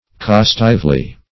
costively - definition of costively - synonyms, pronunciation, spelling from Free Dictionary Search Result for " costively" : The Collaborative International Dictionary of English v.0.48: Costively \Cos"tive*ly\, adv.